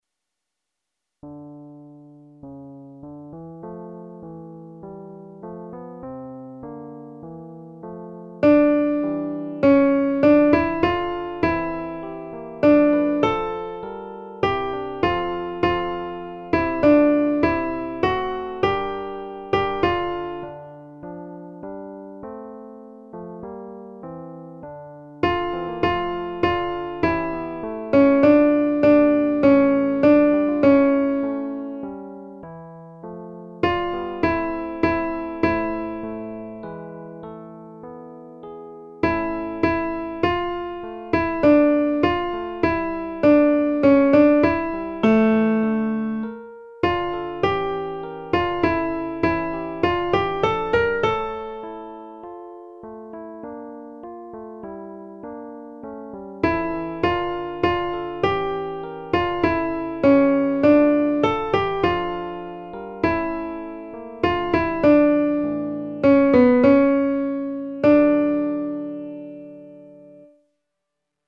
Alto